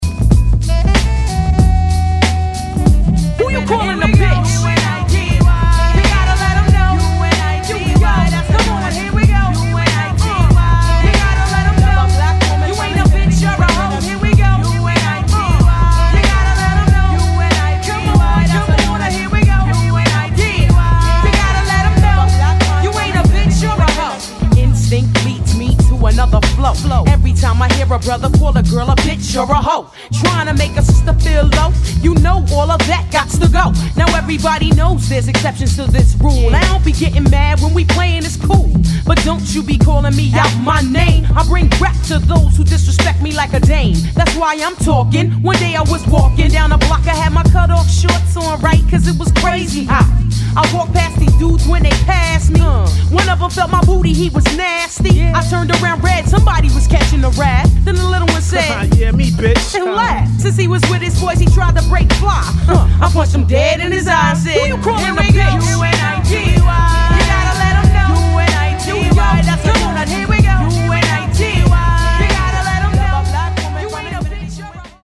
BPM: 94 Time